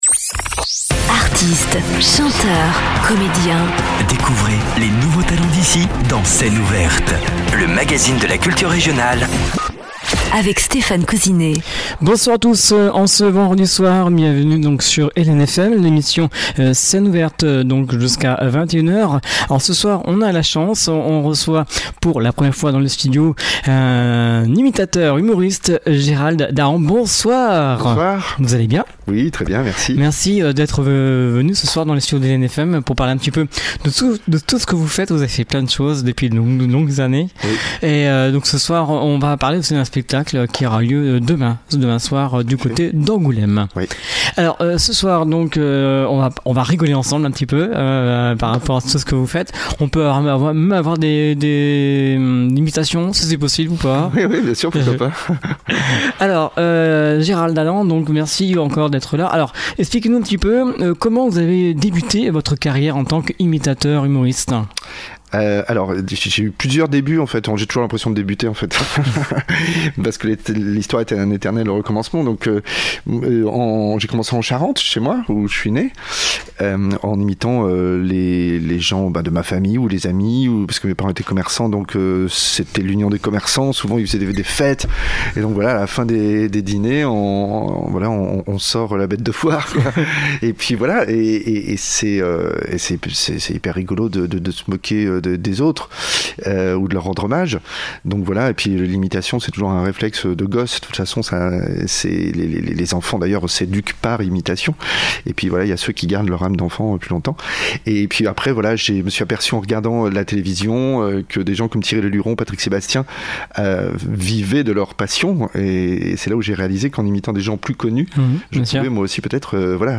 Scène ouverte du 08/12/24 avec l’imitateur Gérald Dahan.